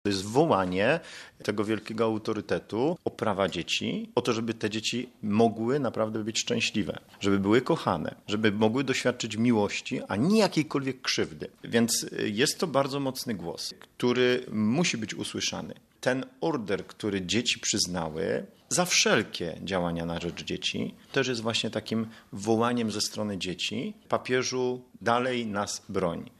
– Papież wielokrotnie staje w obronie dzieci na całym świecie – podkreśla kapłan.